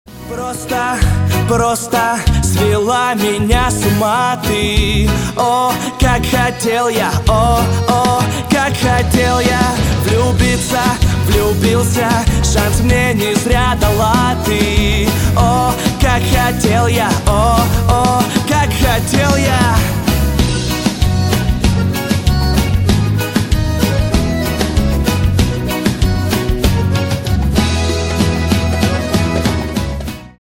• Качество: 256, Stereo
поп
мужской вокал
спокойные
аккордеон